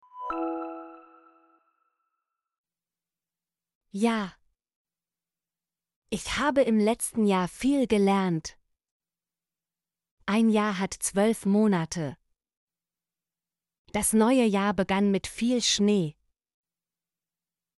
jahr - Example Sentences & Pronunciation, German Frequency List